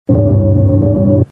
Short tone Klingeltöne
Sound Effects